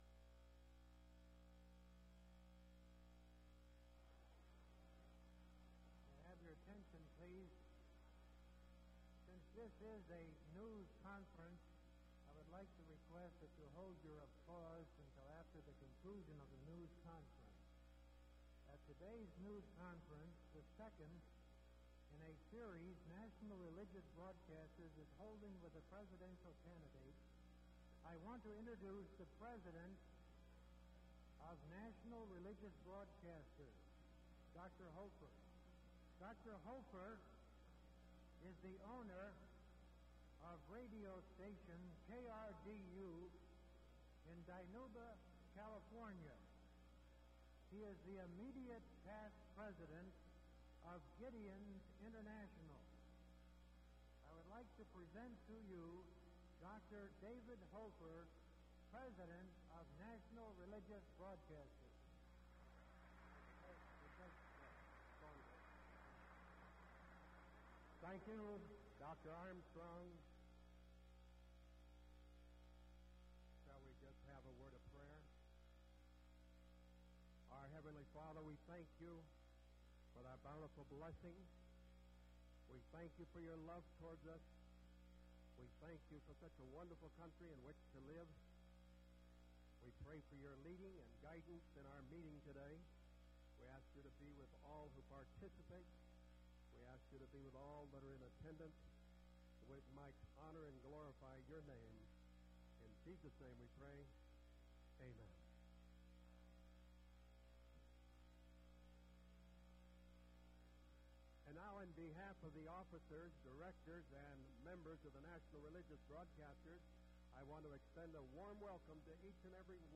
Ronald Reagan Press Conference in Lynchburg, Virginia
Audio Cassette Format.